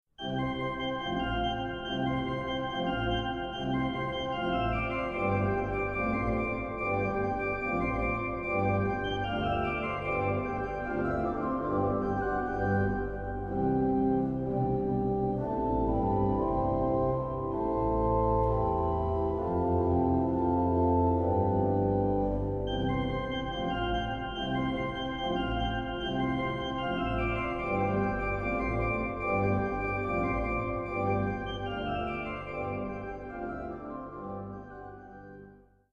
Psalmen uit mijn kinderjaren (Martinikerk te Bolsward)